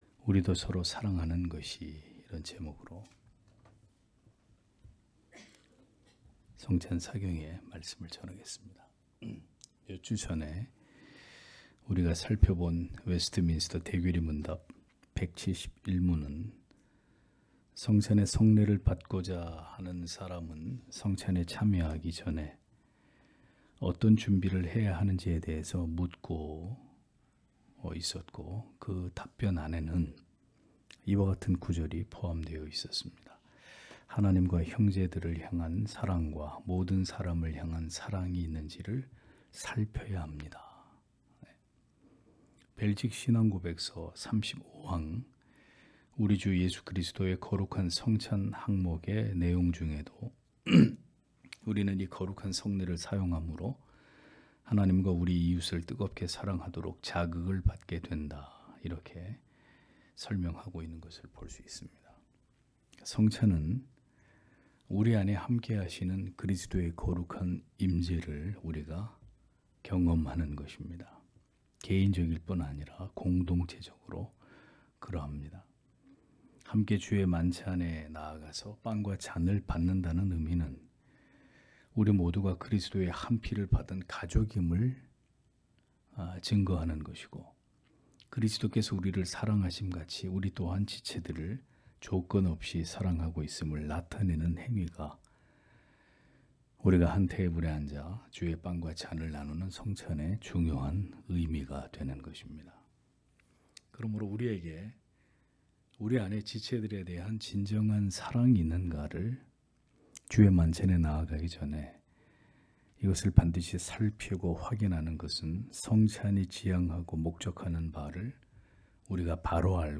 금요기도회 - [성찬 사경회 2] '우리도 서로 사랑하는 것이' (요일 4장 7-11절)